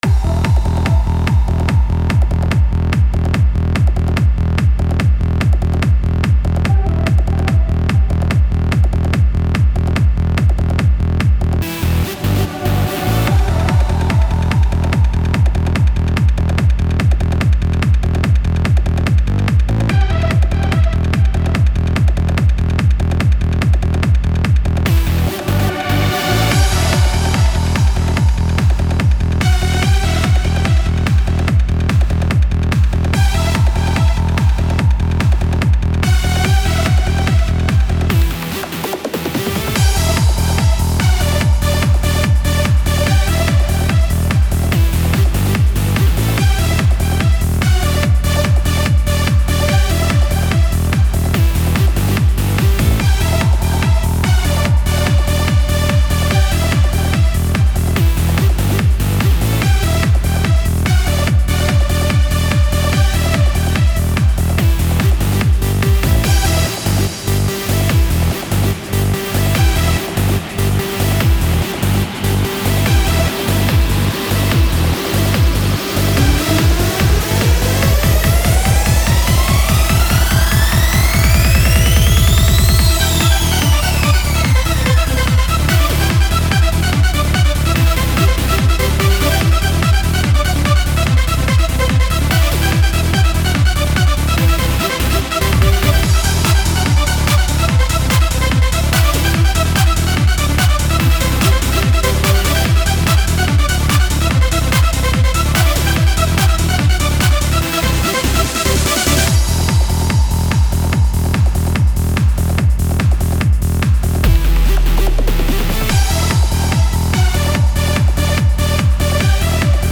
Hard Trance